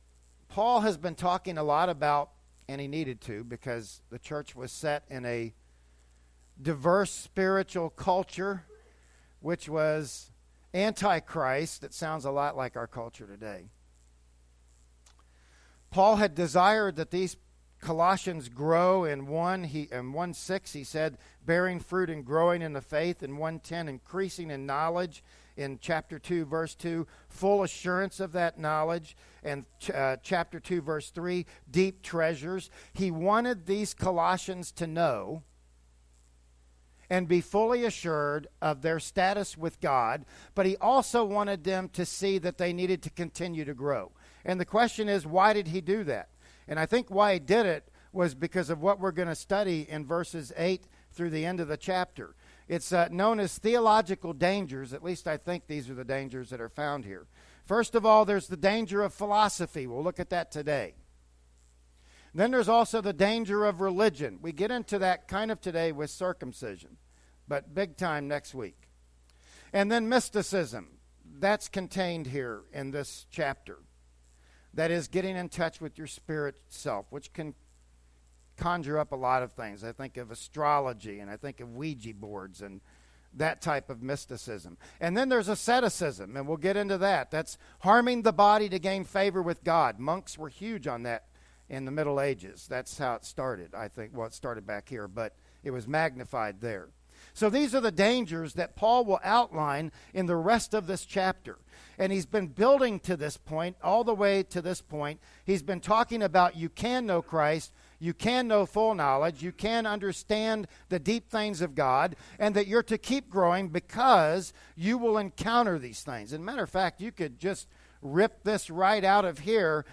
"Colossians 2:8-15" Service Type: Sunday Morning Worship Service Bible Text